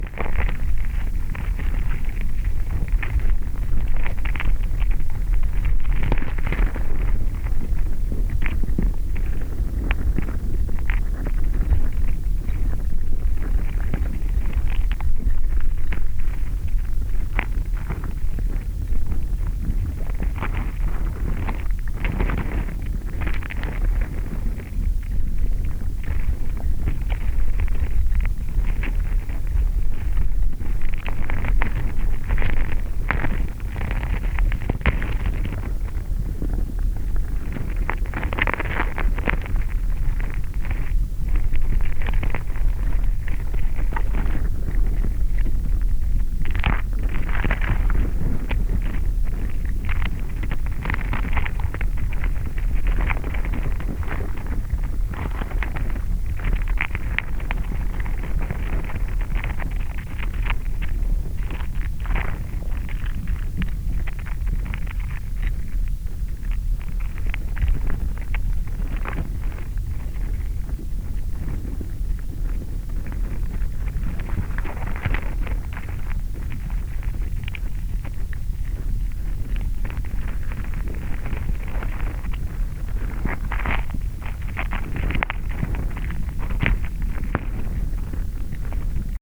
Bruit et vibrations d'une fourmilière
Je n'ai pas encore beaucoup exploré ce dispositif et je n'ai pas assez de connaissances sur le monde des fourmis pour pouvoir interpréter en détail ces enregistrements mais je pense que l'essentiel de ce qu'on entend correspond à des fourmis qui traînent des brindilles plus lourdes qu'elles à proximité du capteur. Le signal s'atténue assez rapidement avec la distance, en tout cas pour des sons "légers" comme les mouvements des fourmis. Pour donner une idée de la sensibilité du dispositif on entend très bien des bruits "lourds" comme des pas humains à 10 ou 20m." (mail 22 juin 2025)
fourmilière et la mare
Enregistrement-fourmilliere.mp3